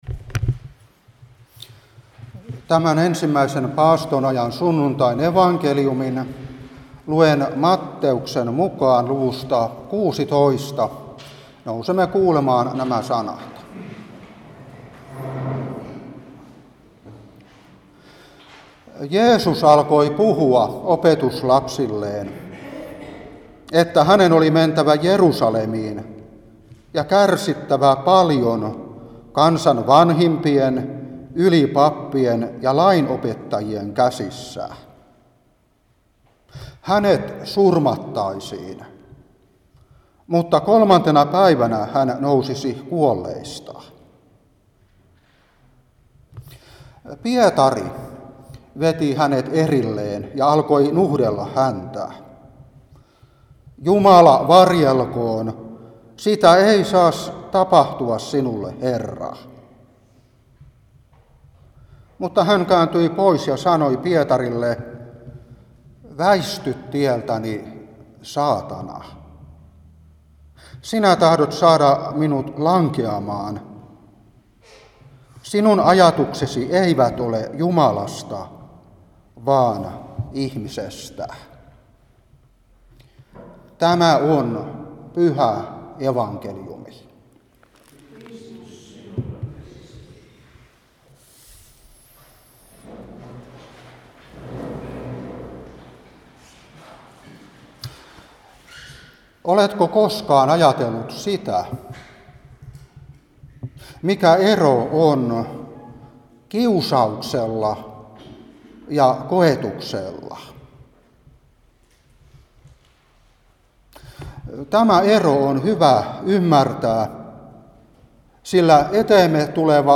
Saarna 2023-2.